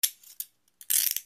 rpthandcuffleftclick.mp3